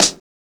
GVD_snr (42).wav